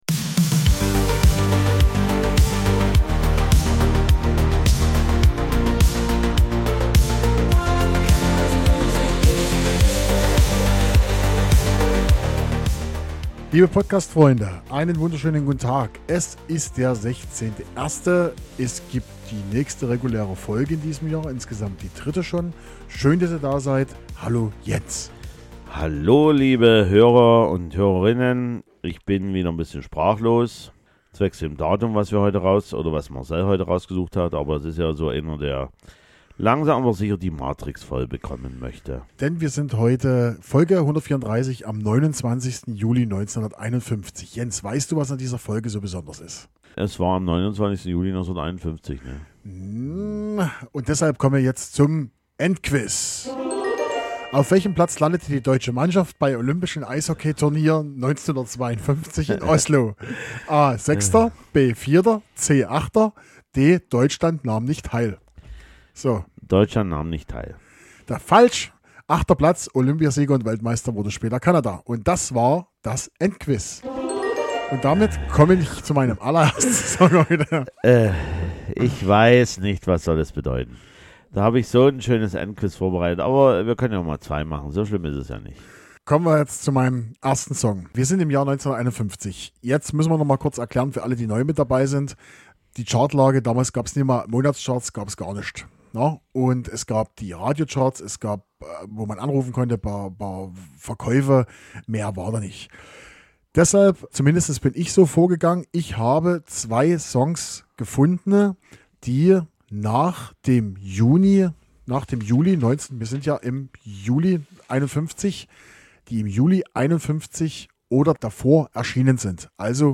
Links KI-Song zu dieser Folge Credits Podcastintro/-outro by Suno Hosted on Acast.